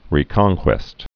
(rē-kŏngkwĕst)